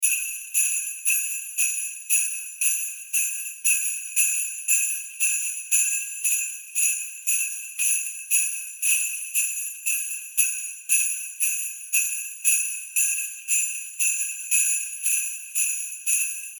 Sleigh Bells Ringing Sound Effect – Festive Holiday Jingle
Sleigh bells ringing sound effect creates a cheerful, festive jingle with Santa Claus, reindeers, and falling snow.
Use it in a seamless loop to add a joyful, festive atmosphere to your content. Tempo 116 bpm.
Sleigh-bells-ringing-sound-effect-festive-holiday-jingle.mp3